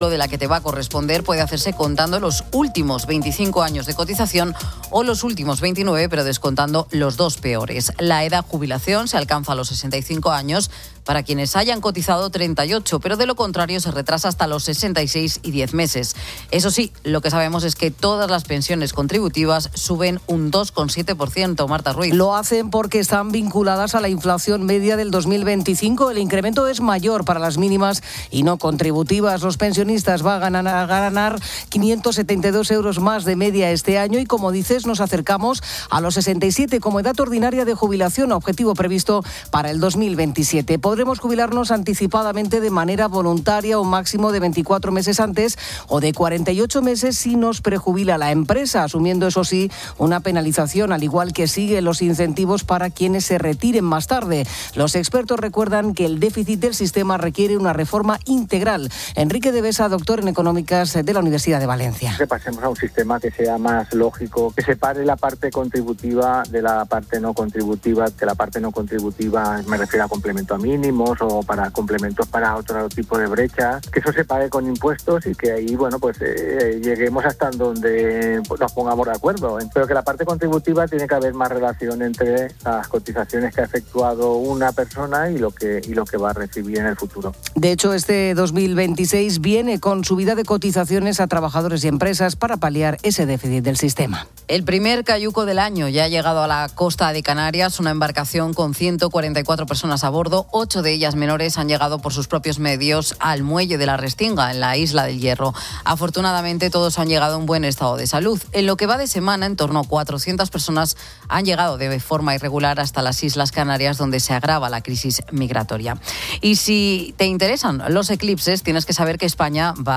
La tertulia explora los viajes.